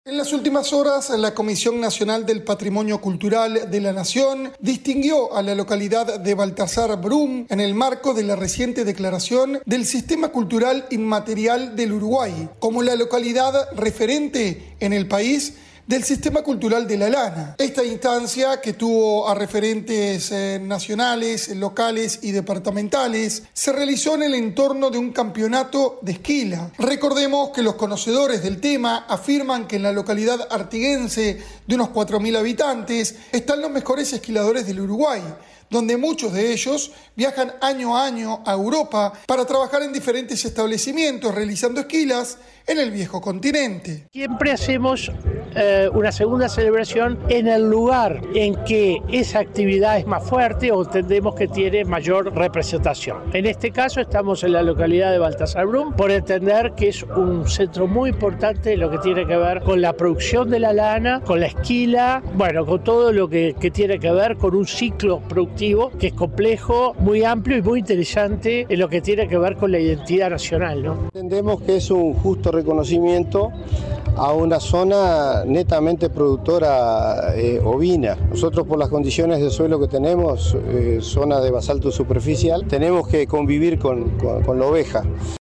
El informe para 970 Noticias
desde Artigas.